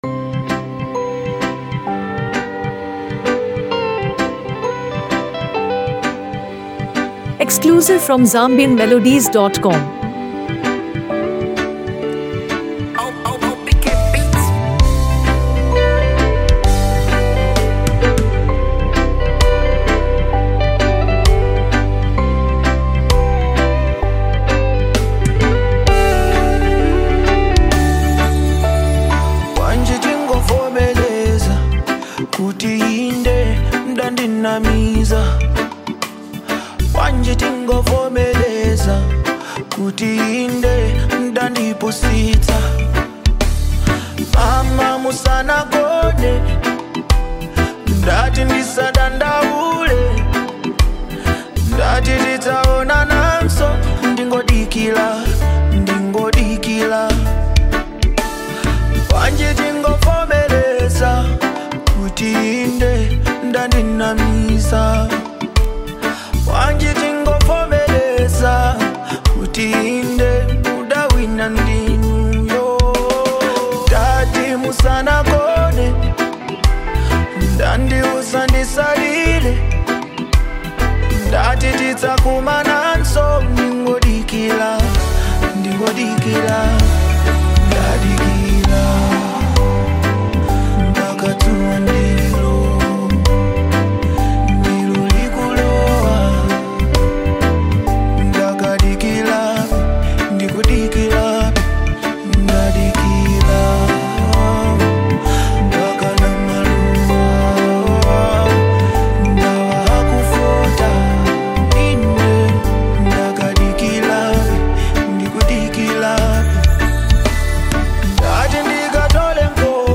authentic Malawian sound
modern Afro-fusion music
polished production